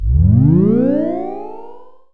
SFX魔法传送音效下载
SFX音效